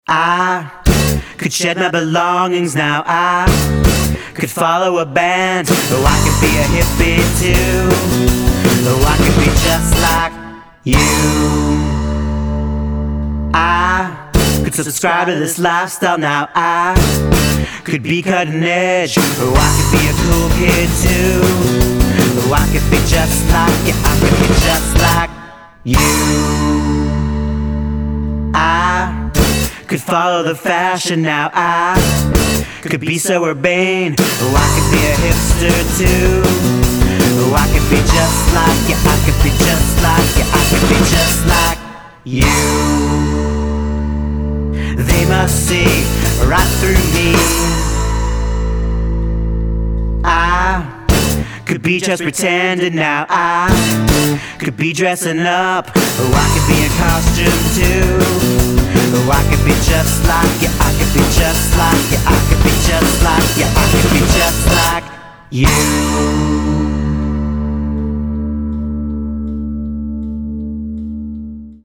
boston's power duo